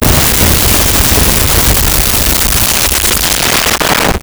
Explosion Large Bright
Explosion Large Bright.wav